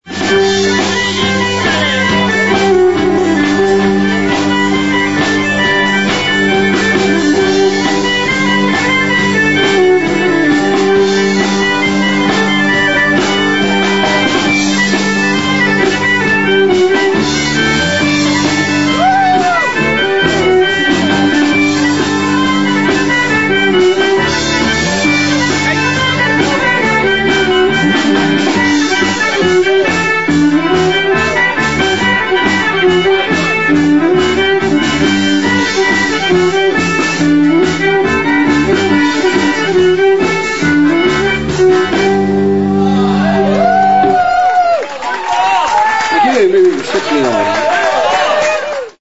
Powerful ceili band performing instrumental dance music.
melodeon